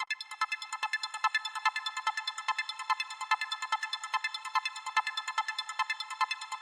描述：ake type loop VST: Uphoria Instrument:小槌
标签： 145 bpm RnB Loops Synth Loops 1.11 MB wav Key : Unknown
声道立体声